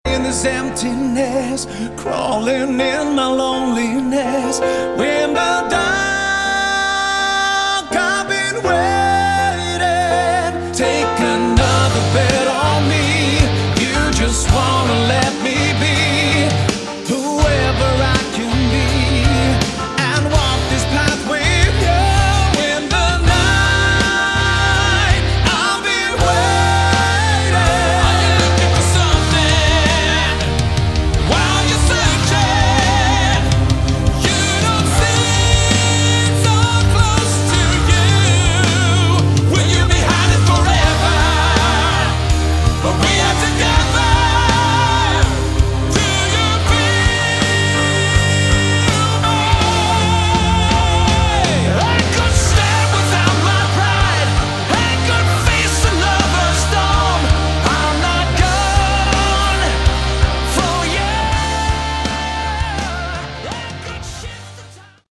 Category: AOR
vocals
bass, additional keyboards, guitars,, backing vocals
guitars
drums